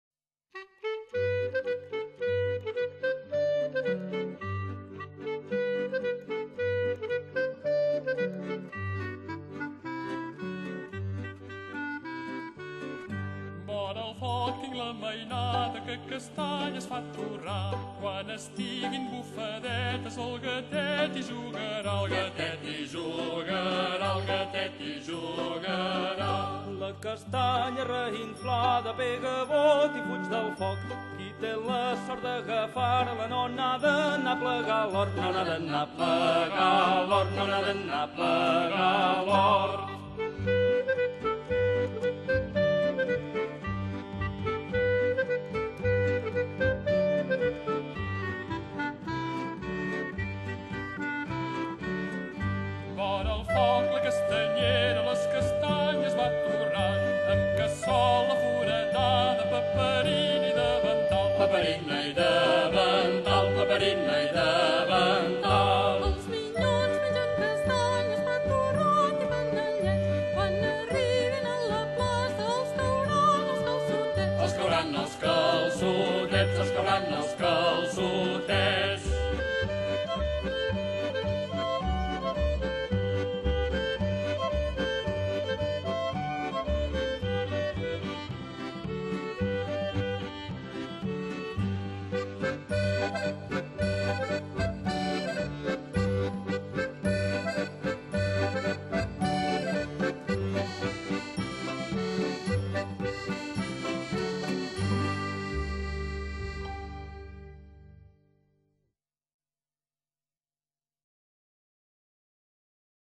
Tots Sants - Danses
Tradicional catalana